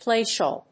音節pláy・schòol
アクセント・音節pláy・schòol